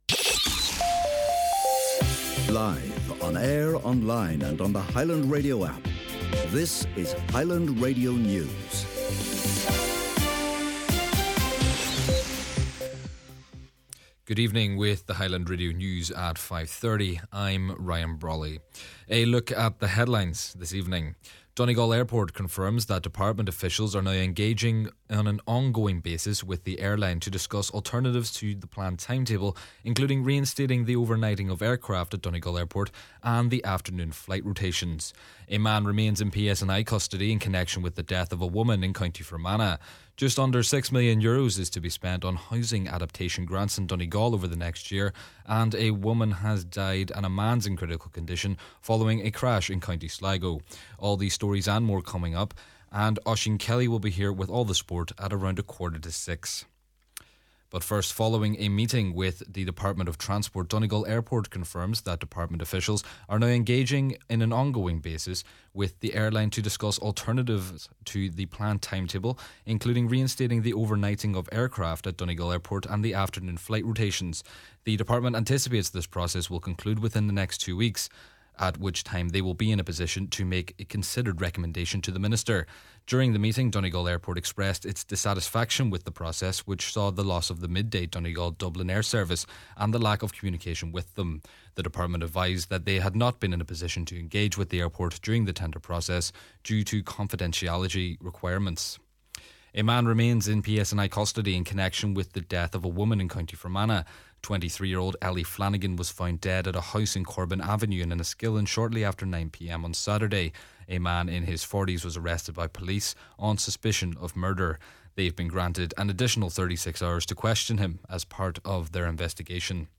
Main Evening News, Sport and Obituary Notices – Monday, March 9th